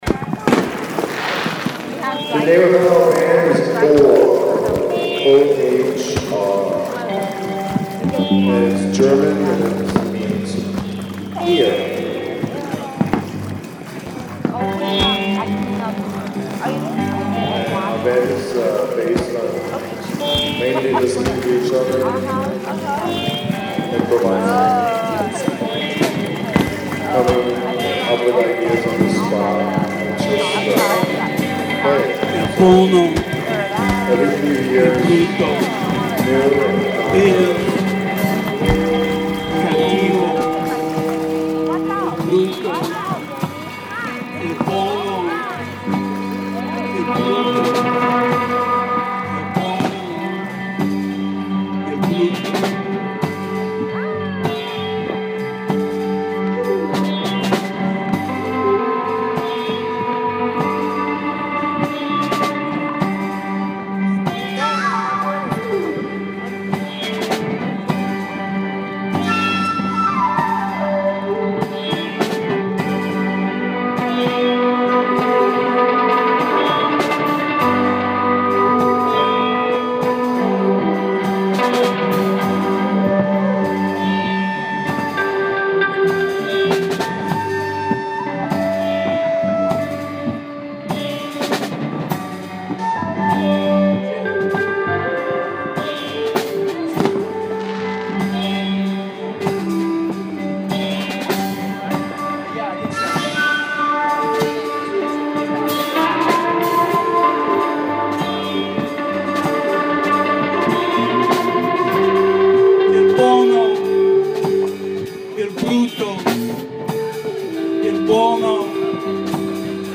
Intro and Full Moon Intro/sax
bass/keyboard/vocals
drums
sitar/voice
guitar/voice
ALL MUSIC IS IMPROVISED ON SITE